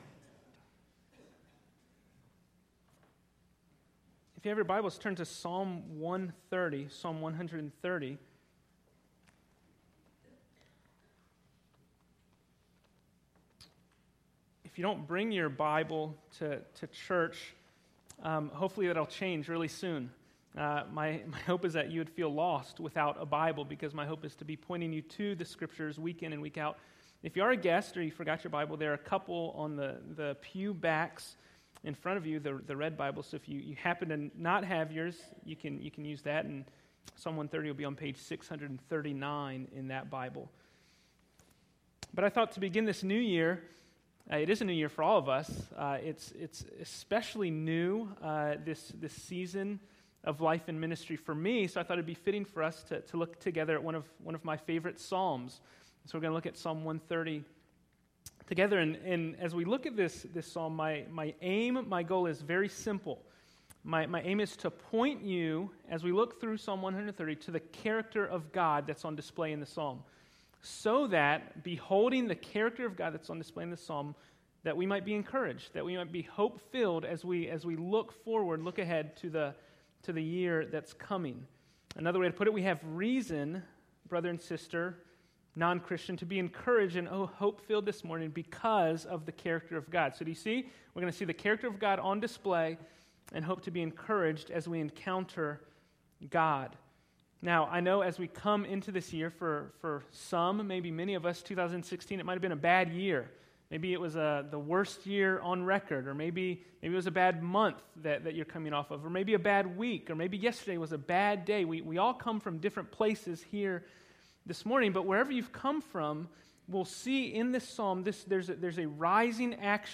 Fox Hill Road Baptist Church Sermons